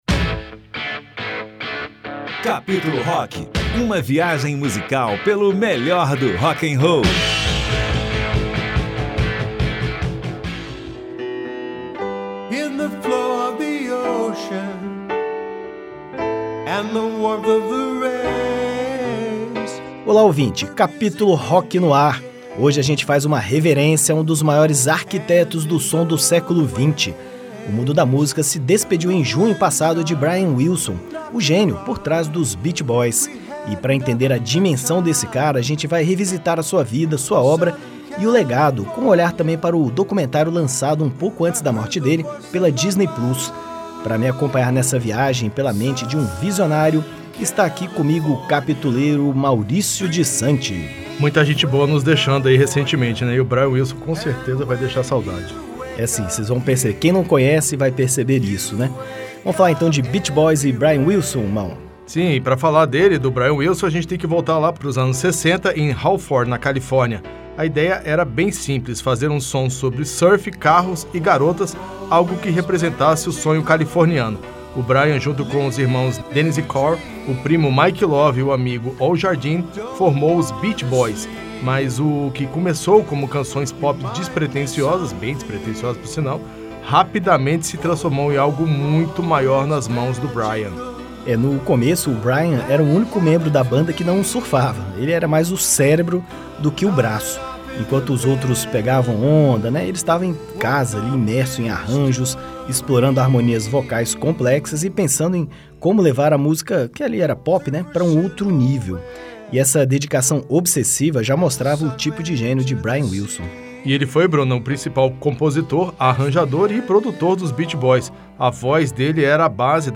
os apresentadores